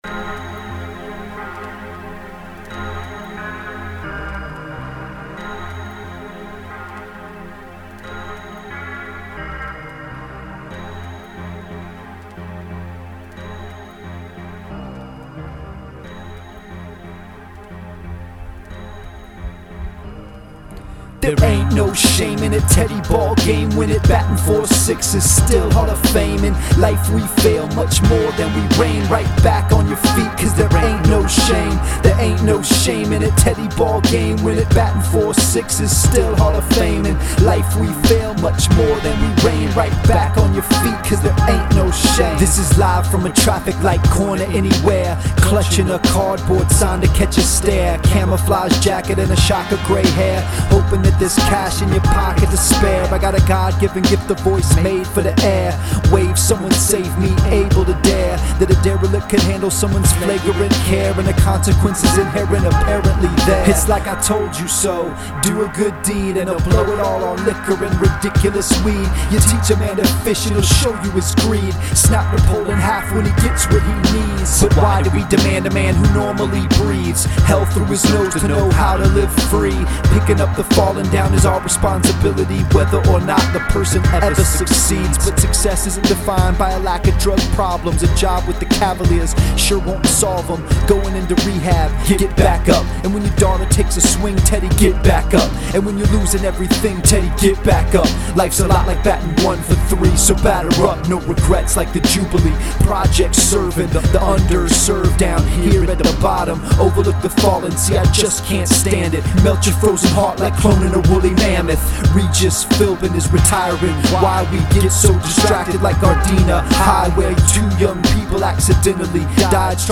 Thanks to the Twitter contest winners who provided inspiration for this Frankenstein monster of a song.